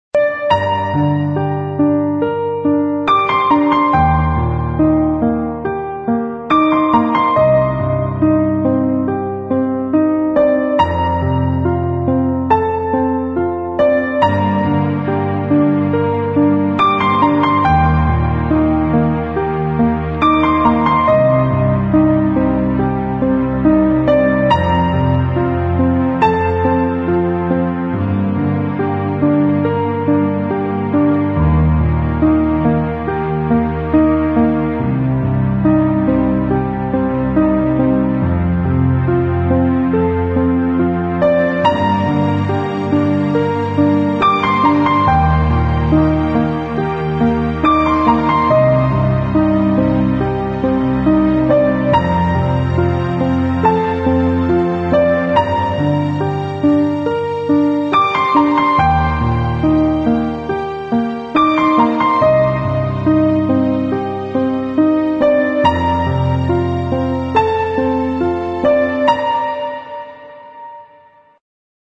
描述：轻柔安静的钢琴乐章。
Looped Audio 循环音频没有
Sample Rate 抽样率16位立体声, 44.1 kHz
Tempo (BPM) 节奏(BPM)70